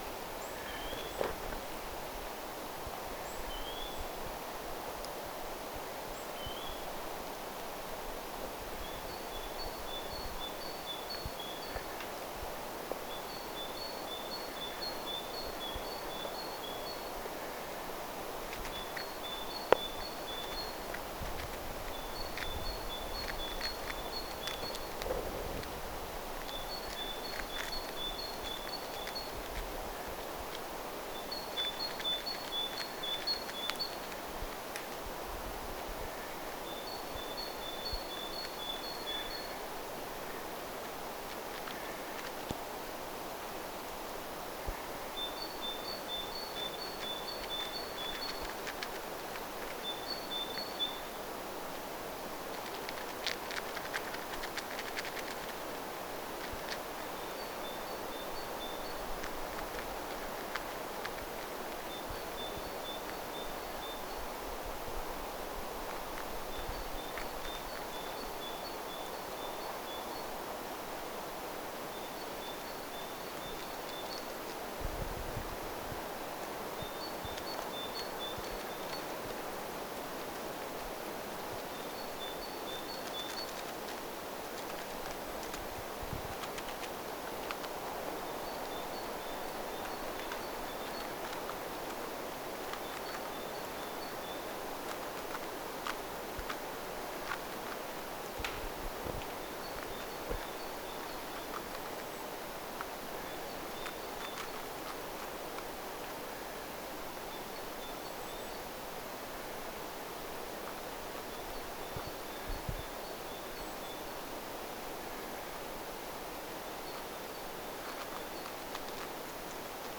talitiaisen laulua,
jokin otus rapistelee ihan lähellä
talitiaisen_laulua_joku_pikkuotus_rapistelee.mp3